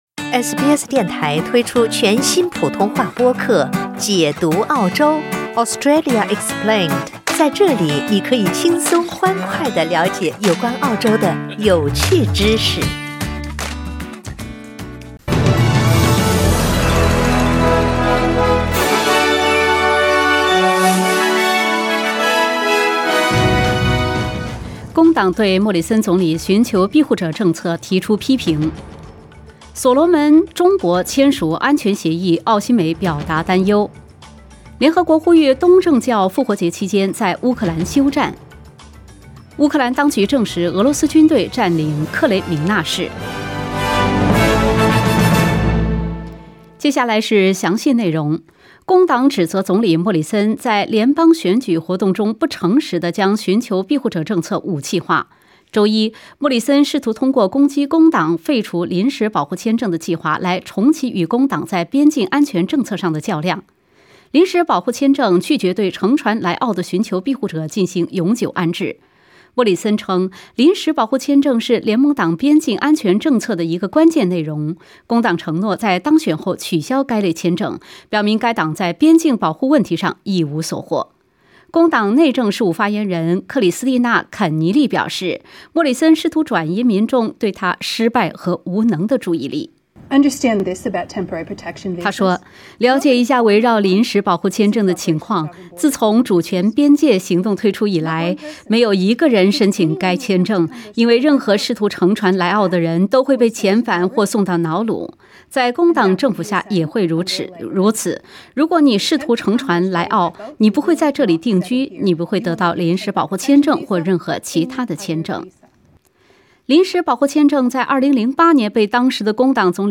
SBS早新闻（4月20日）